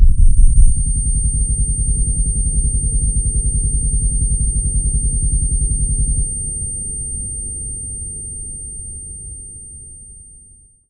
deafness.ogg